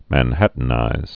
(măn-hătn-īz, mən-)